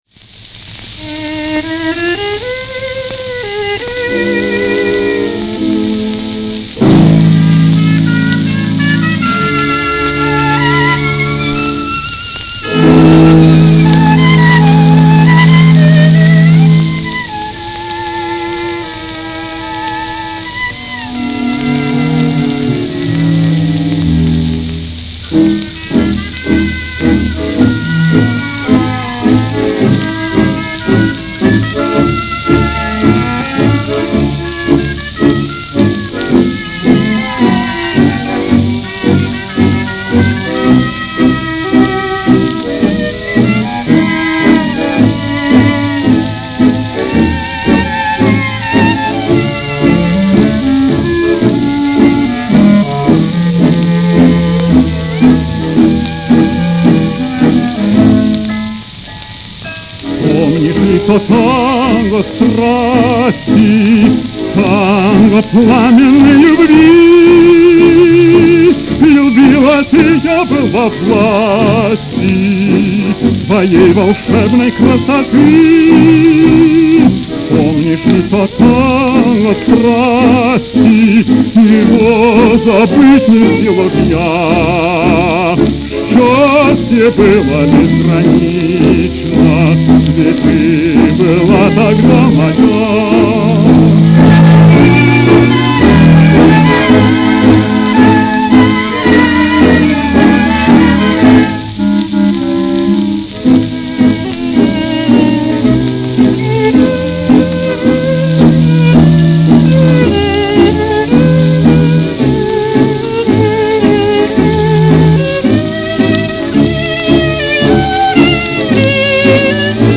Каталожная категория: Танцевальный оркестр с пением |
Жанр: Танго
Вид аккомпанемента: Оркестр
Место записи: Германия |
Скорость оцифровки: 78 об/мин |